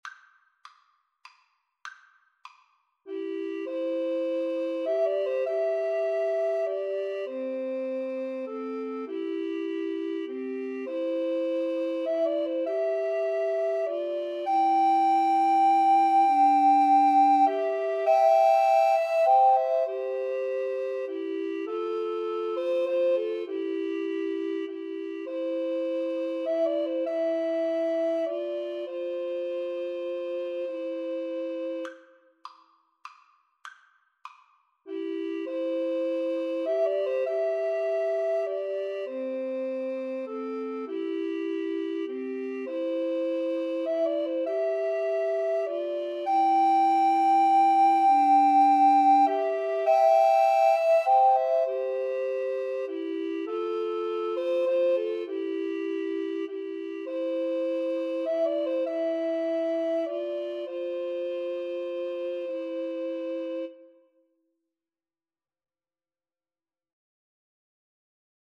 Amazing Grace is a Christian hymn with words written by the English poet and clergyman John Newton (1725–1807), published in 1779.
C major (Sounding Pitch) (View more C major Music for Recorder Trio )
3/4 (View more 3/4 Music)
Recorder Trio  (View more Easy Recorder Trio Music)
Traditional (View more Traditional Recorder Trio Music)